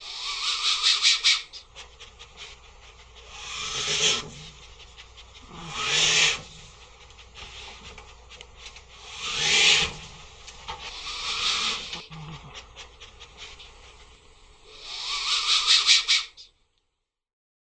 Муравьед